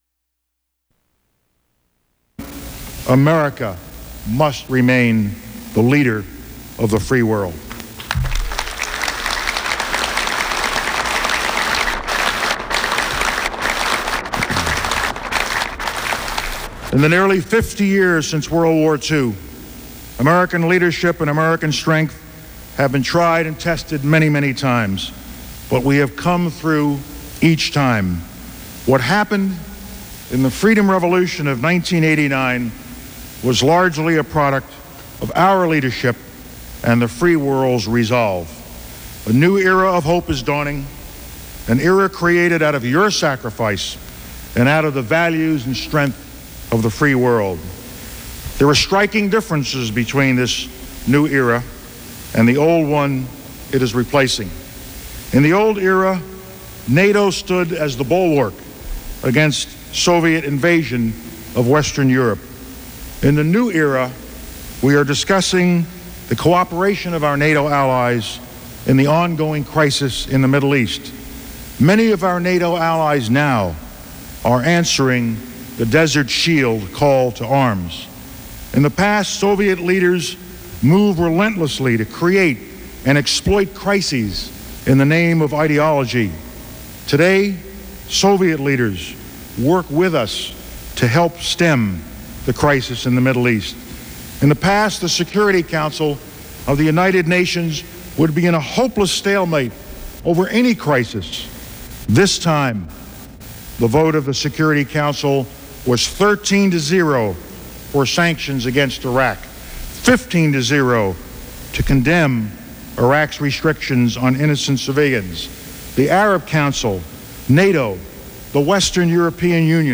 Chairman of the U.S. Joint Chiefs of Staff Colin Powell addresses the Veterans of Foreign Wars Convention